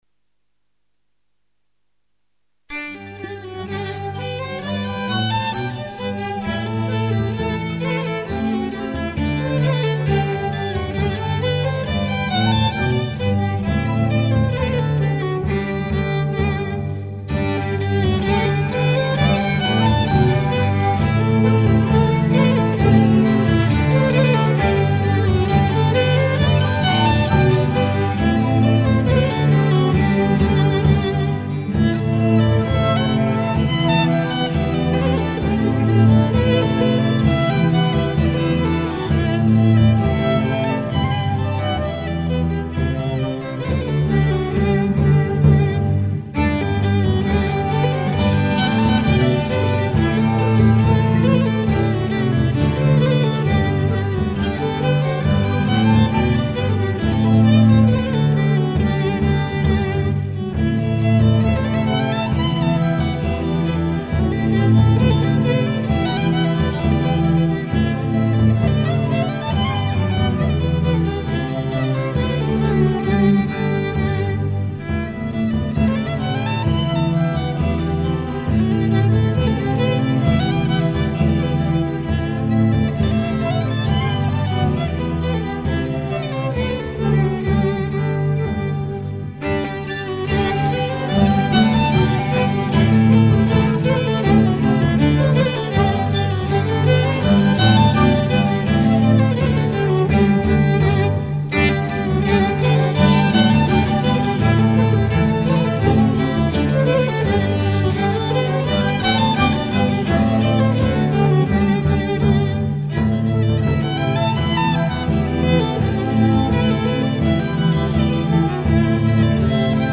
audioArdlamon Irlanda (violino solista